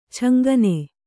♪ chaŋgane